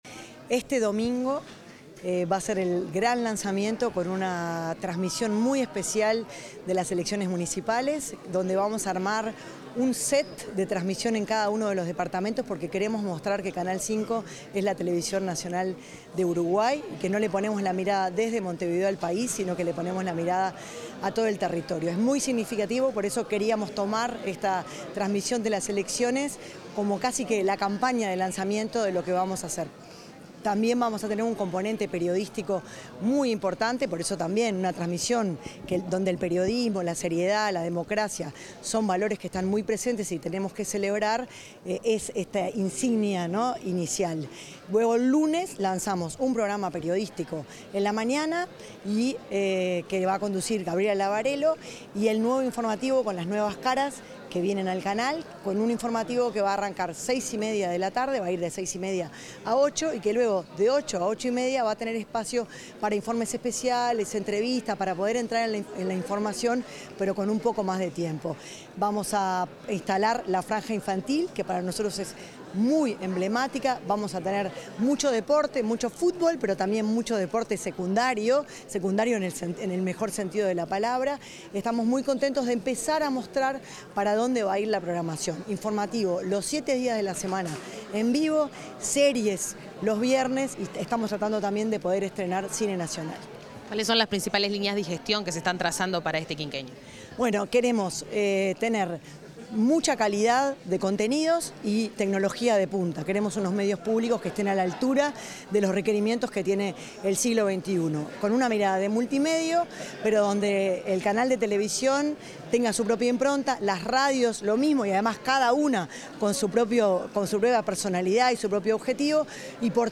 Declaraciones de la presidenta del Secan y directora de Canal 5, Erika Hoffmann
La presidenta del Servicio de Comunicación Audiovisual Nacional (Secan), Erika Hoffmann, efectuó declaraciones, este jueves 8, durante la presentación